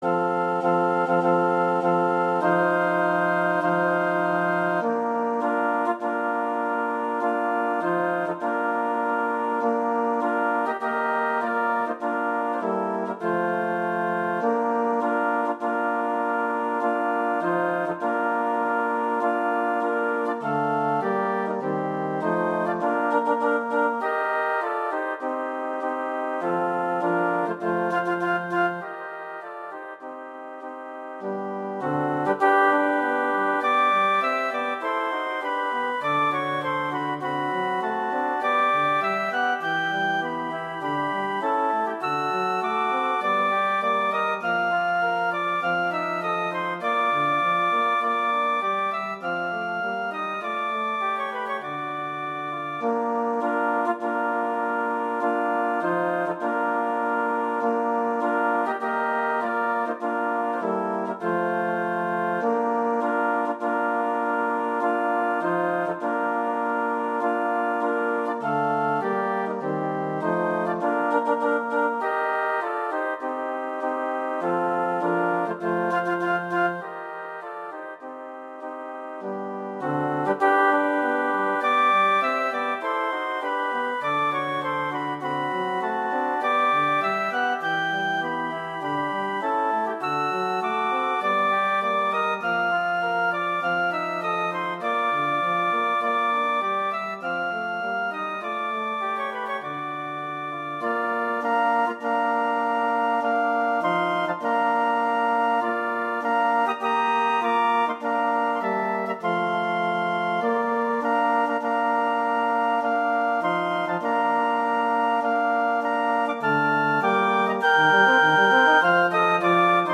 Instrumentation: flute quartet
arrangements for flute quartet
2 flutes, alto flute.
wedding, traditional, classical, festival, love, french